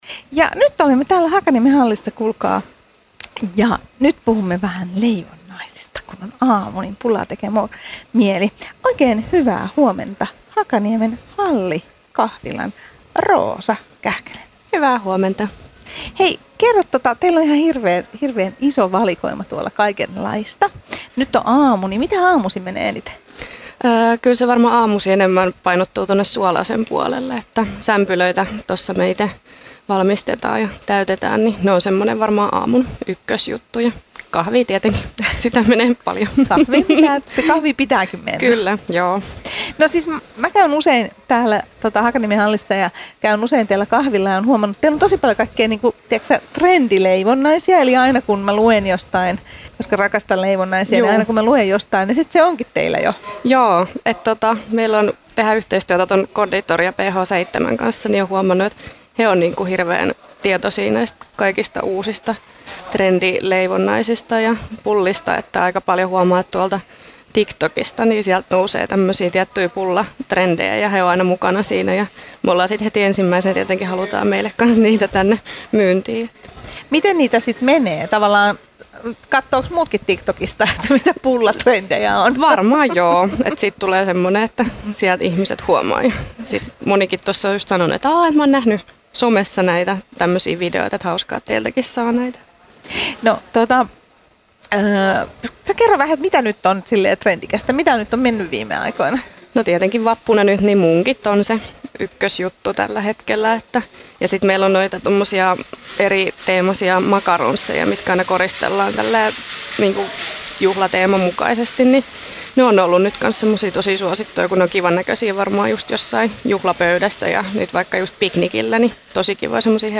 Kaupunkitiloissa Hakaniemen hallissa!